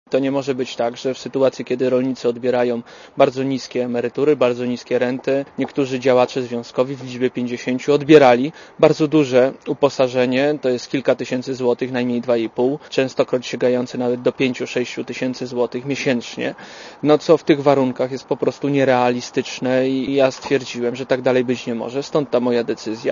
Komentarz audio (92Kb)